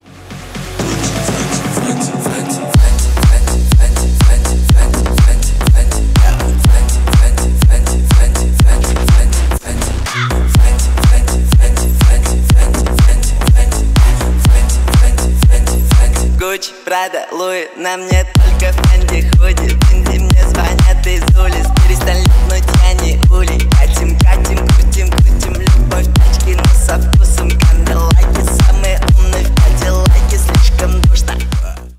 Ремикс # Танцевальные
громкие